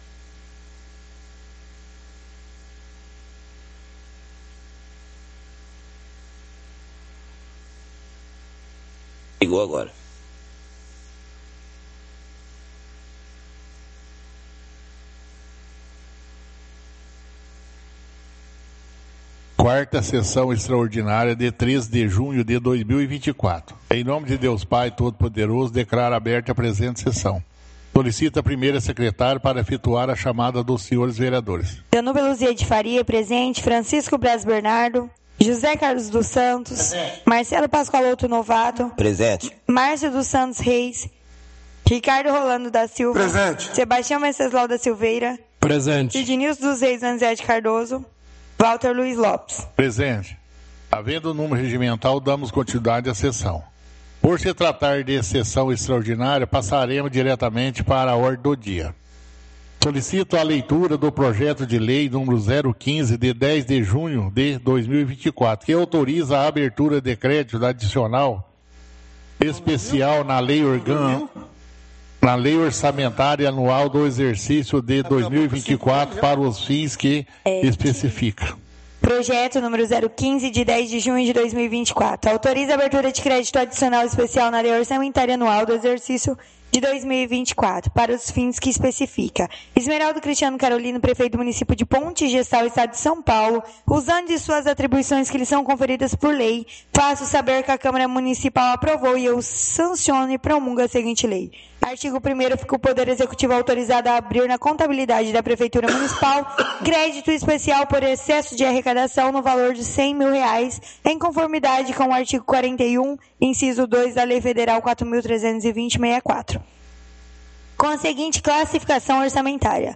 Áudio da 3ª Sessão Extraordinária – 13/06/2024